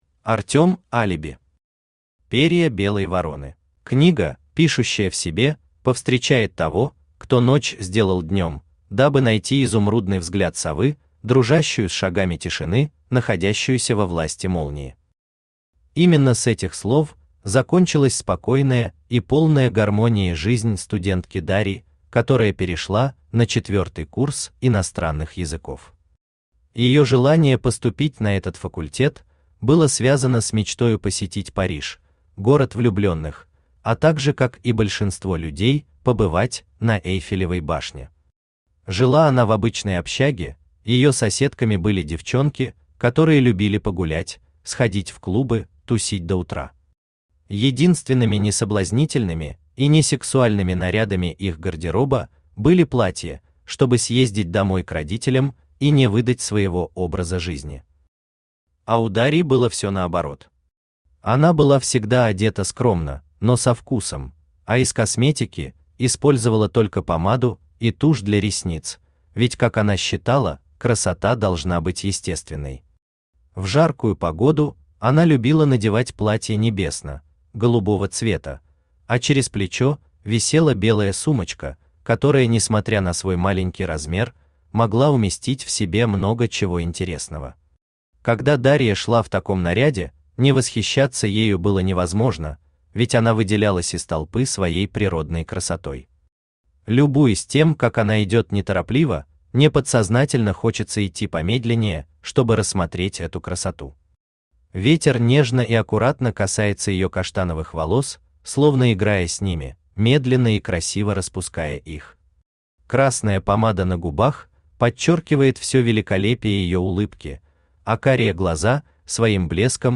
Аудиокнига Перья белой вороны | Библиотека аудиокниг
Aудиокнига Перья белой вороны Автор Артем Алиби Читает аудиокнигу Авточтец ЛитРес.